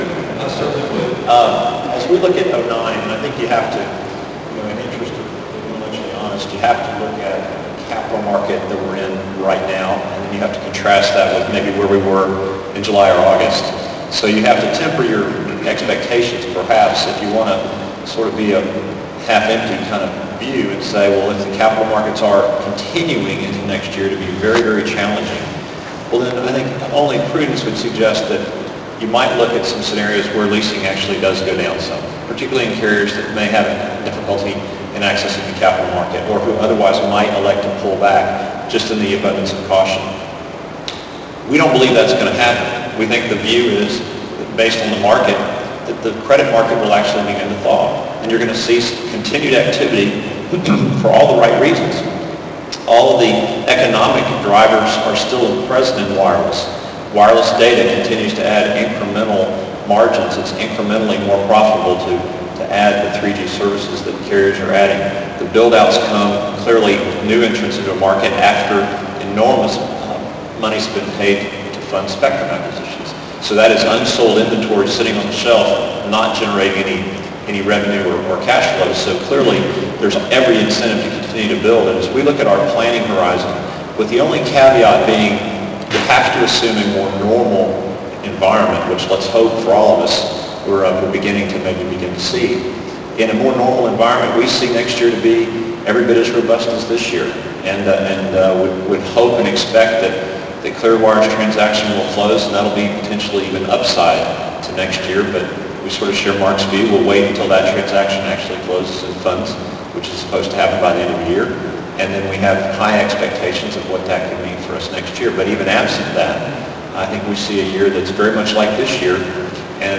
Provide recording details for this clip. Speaking during the PCIA's final day of The Wireless Infrastructure Show in Hollywood, FL yesterday, the titans of towers were enthusiastic about lease-up opportunities for the coming year.